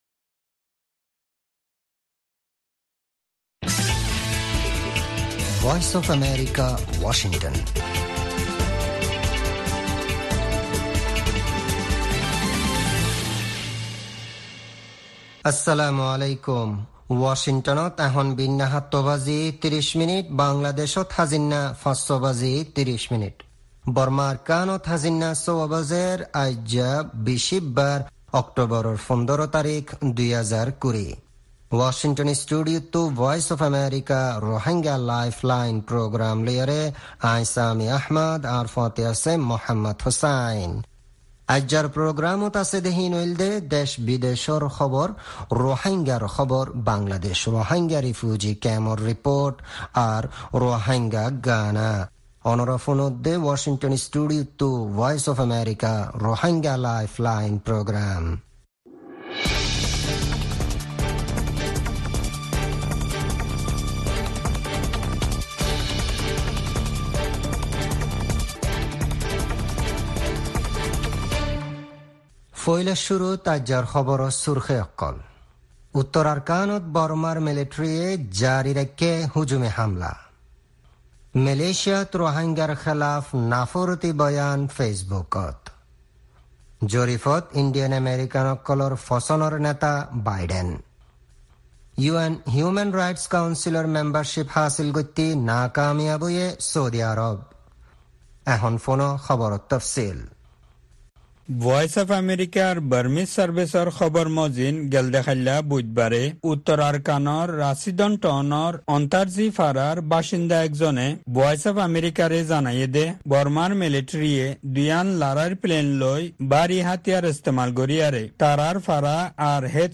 Rohingya “Lifeline” radio